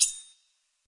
剑鞘
描述：护剑，用真剑记录;试图过度夸大声音，以便更好地听到。
标签： 包住 声音效
声道立体声